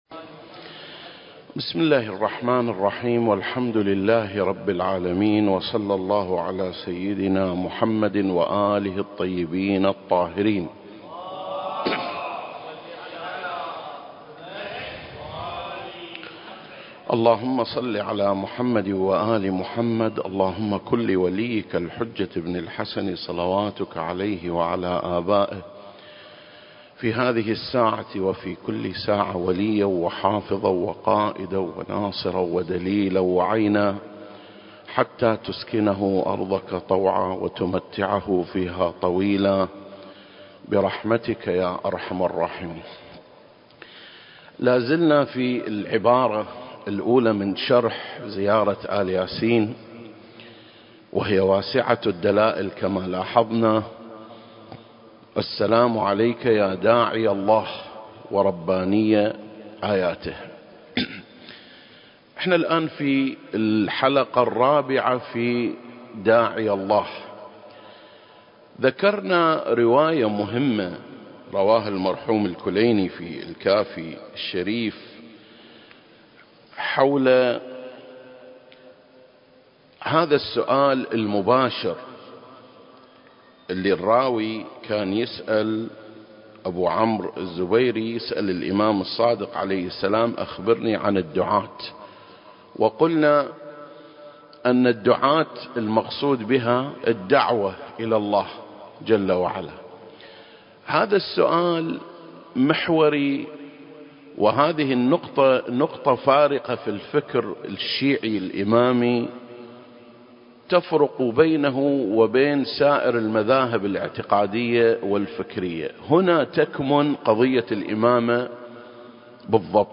سلسلة: شرح زيارة آل ياسين (26) - يا داعي الله (4) المكان: مسجد مقامس - الكويت التاريخ: 2021